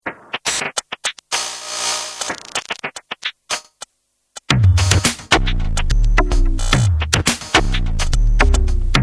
It's sample-based music in techno-style.